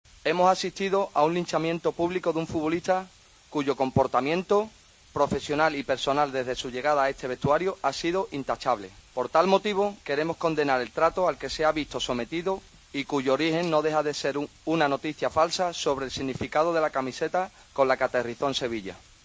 El capitán del Betis ha leído un comunicado en nombre de toda la plantilla verdiblanca para mostrar su apoyo a Roman Zozulya y defender su profesionalidad.